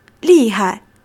li4--hai.mp3